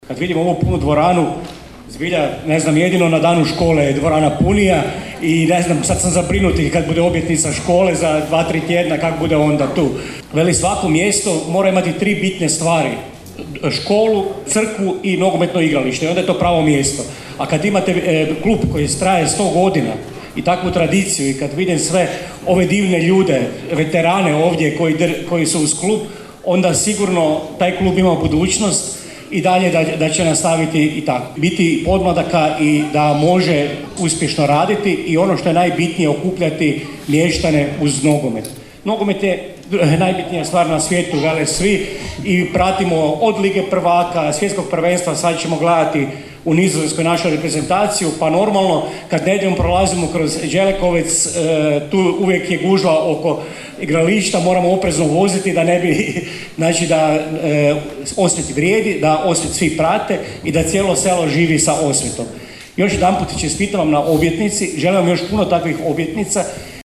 U prepunom Domu kulture Pajo Kanižaj u Đelekovcu je održana sportsko-povijesna večer u povodu obilježavanja 100. rođendana Nogometnog kluba Osvit Đelekovec.
– rekla je načelnica Općine Đelekovec Lara Samošćanec, a zamjenik župana KKŽ Ratimir Ljubić dodao;